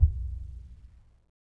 BDrumNewhit_v1_rr1_Sum.wav